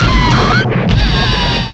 pokeemerald / sound / direct_sound_samples / cries / heatran.aif